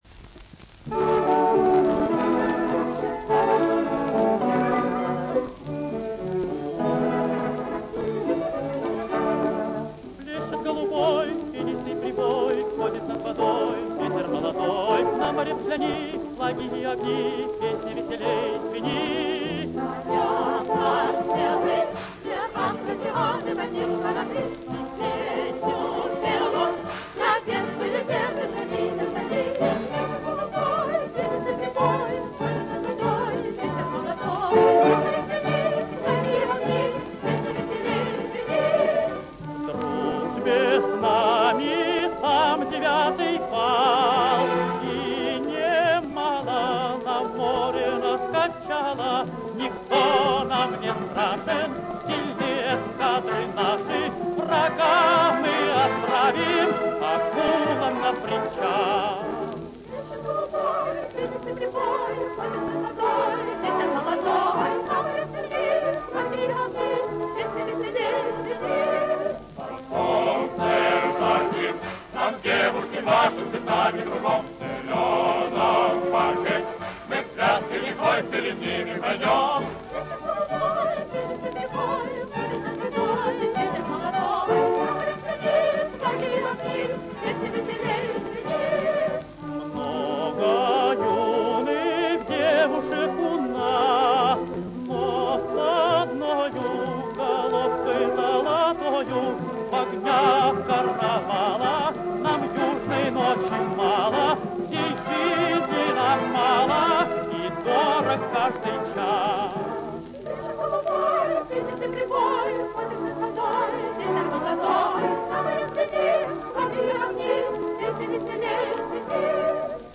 Главная / Computer & mobile / Мелодии / Марши
Задорная довоенная песня о морском карнавале, 1939г.